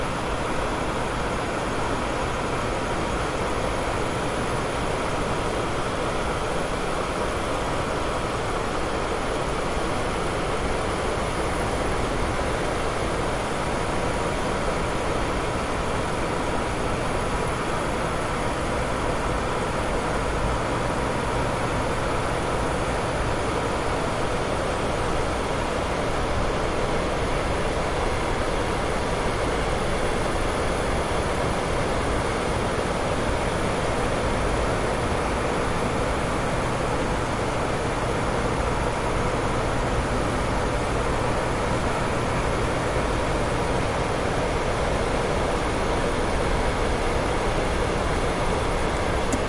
扇子2
描述：一个在我的客厅里奔跑的ocillating风扇。
Tag: 风扇吹 风扇运行 风扇 吹制 运行